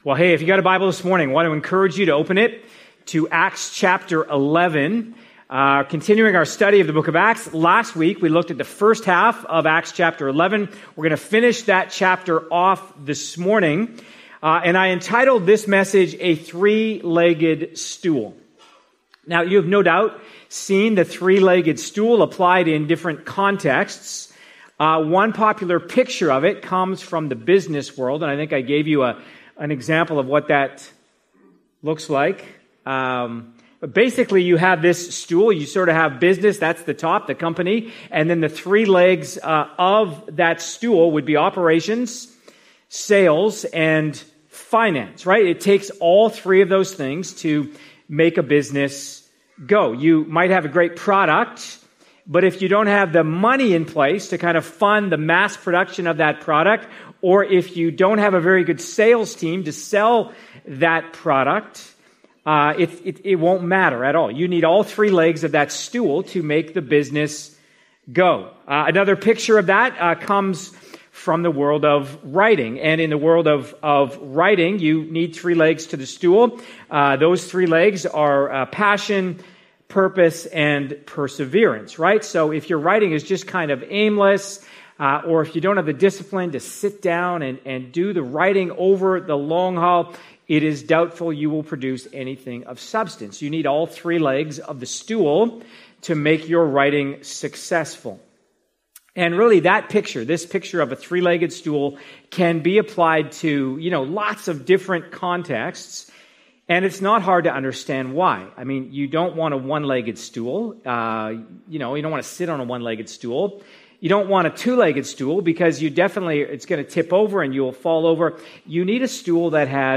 Questions for Community Groups and Personal Reflection Part of our series, ACTS: Mission & Message (click for more sermons in this series).